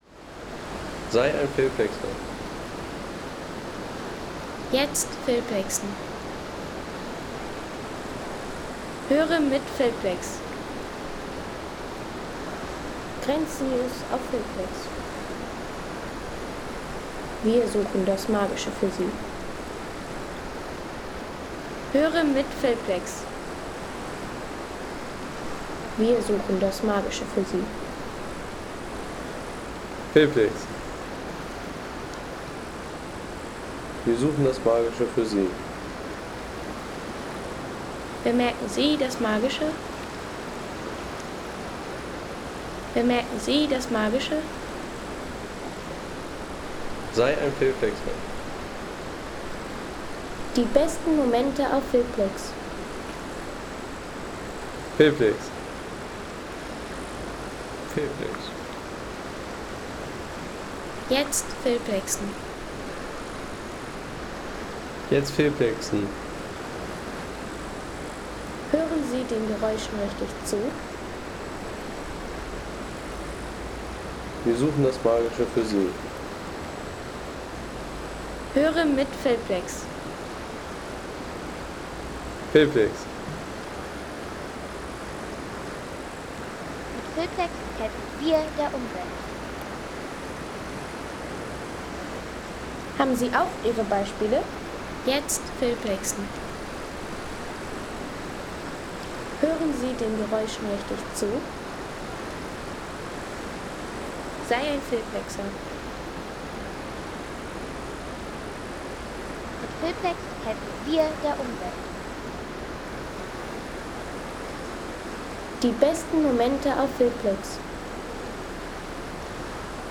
River Moment Enns – Water & Train Sounds from Gesäuse
Gentle river and train ambience from the Enns riverbank – recorded at Weidendom in Austria's Gesäuse National Park.
Peaceful river recording at the banks of the Enns near Weidendom – gentle current and passing train in the heart of Gesäuse National Park.